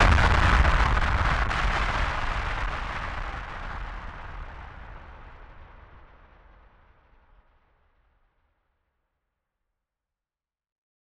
BF_DrumBombB-09.wav